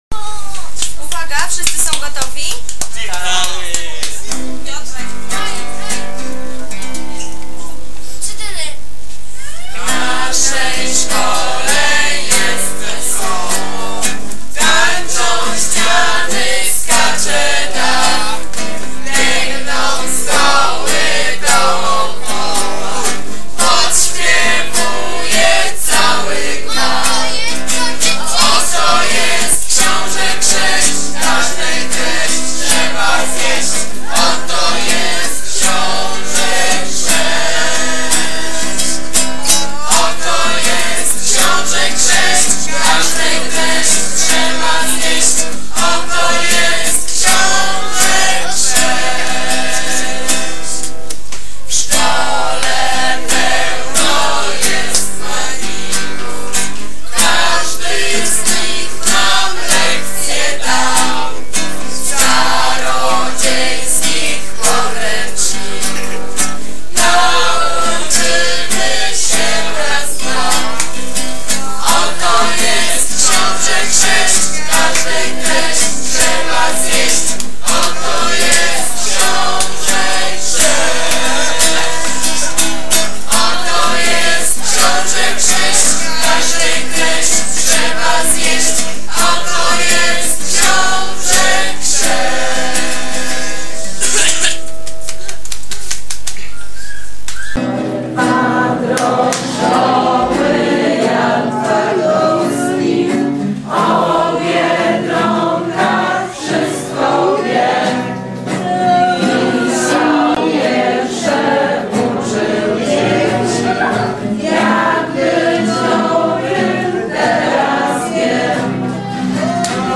Hymn szkół
hymn-szkoly.mp3